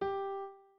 01_院长房间_钢琴_05.wav